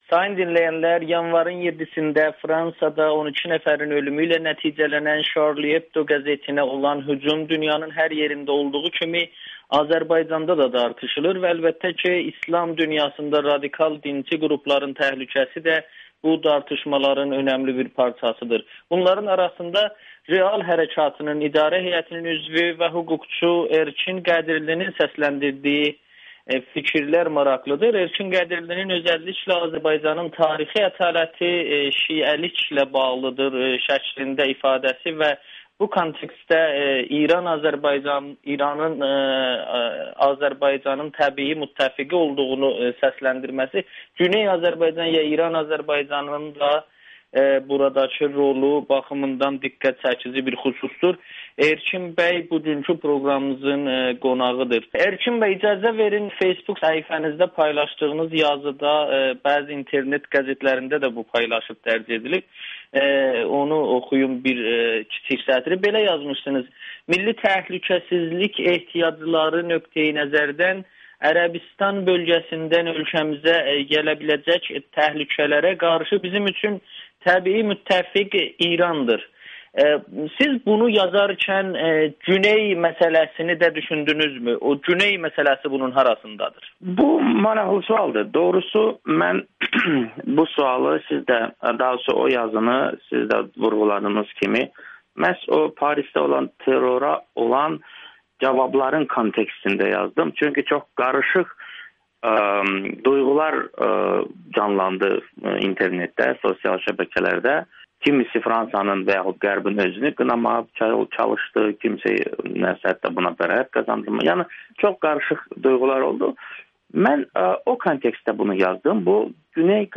Charlie Hebdo, Rafiq Tağı, Salman Rüşdi və İran-Azərbaycan arasında məzhəbi əlaqə [Audio-Müsahibə]